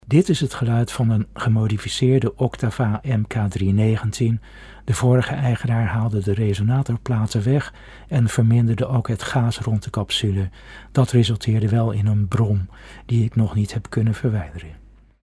MK 319 (met low cut)
Oktava MK319 sound NL met low cut.mp3